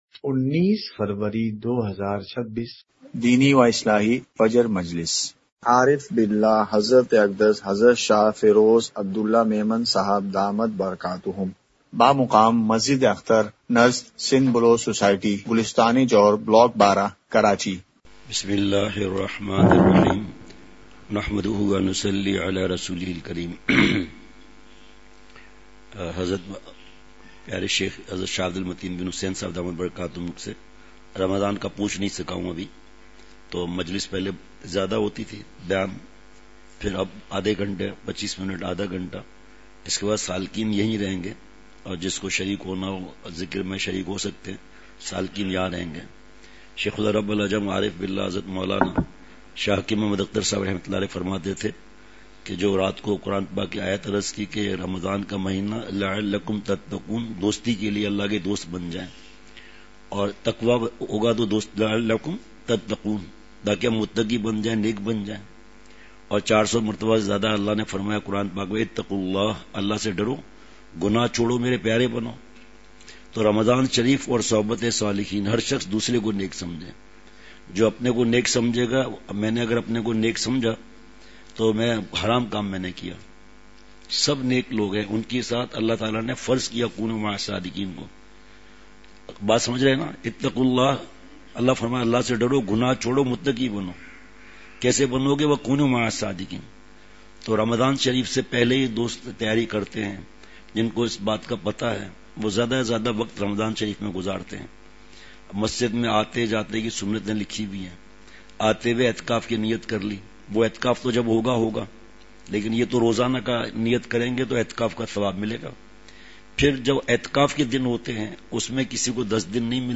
اصلاحی مجلس
*مقام:مسجد اختر نزد سندھ بلوچ سوسائٹی گلستانِ جوہر کراچی*